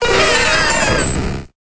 Cri de Mew dans Pokémon Épée et Bouclier.